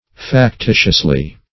-- Fac-ti"tious*ly, adv.